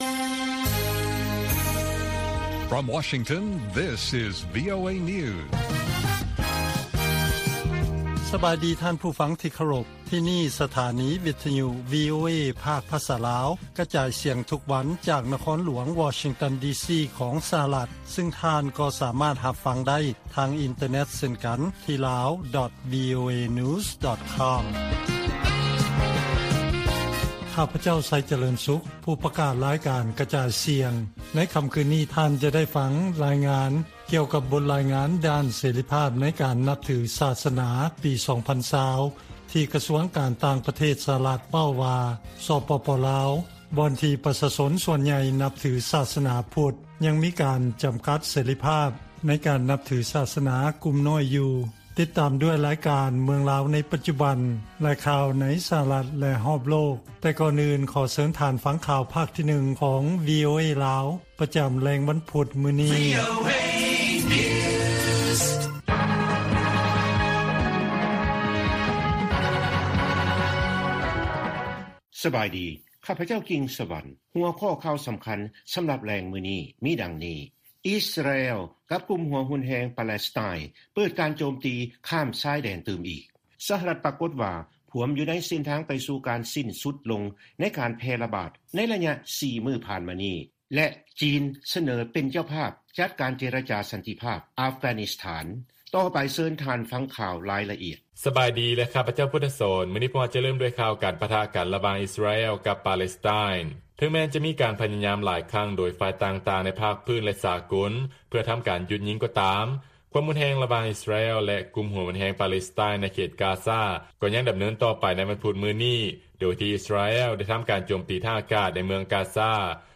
ວີໂອເອພາກພາສາລາວ ກະຈາຍສຽງທຸກໆວັນ.